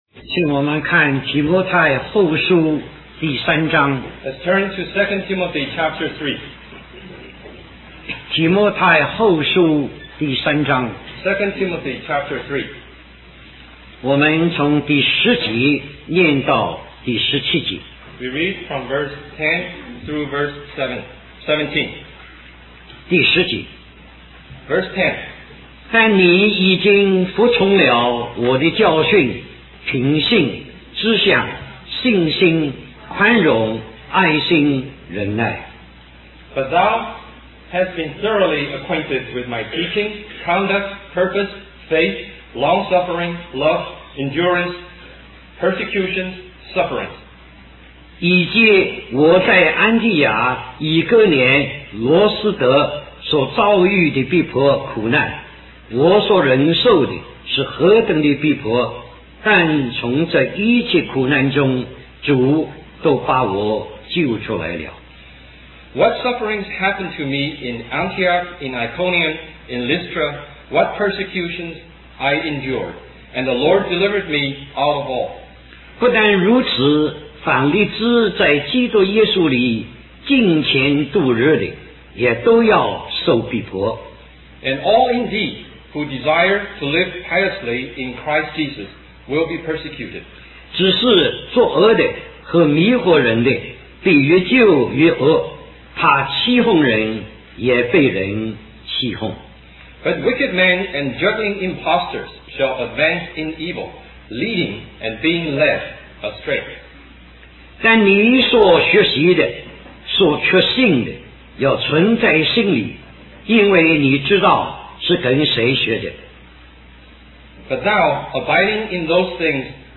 Conference at Bible Institute of Los Angeles